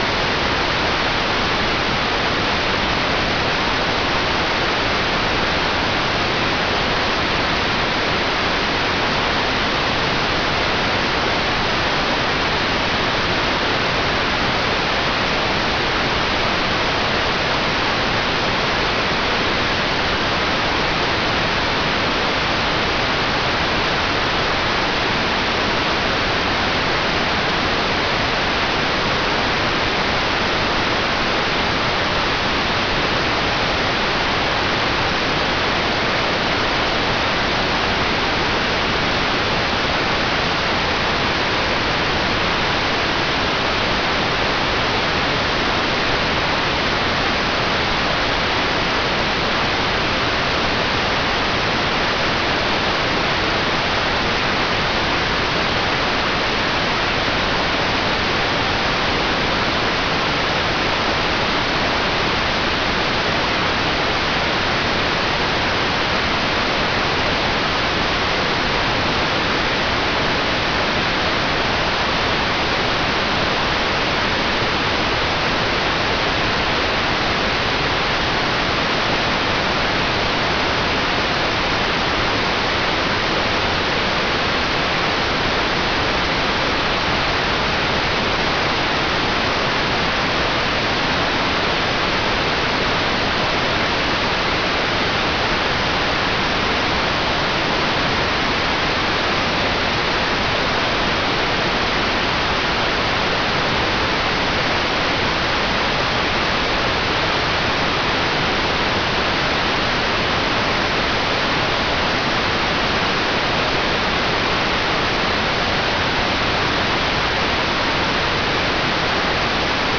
Hear the audio from this pass or download the demodulated audio and decode the images yourself.